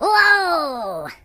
project_files/HedgewarsMobile/Audio/Sounds/voices/Pirate/Whoopsee.ogg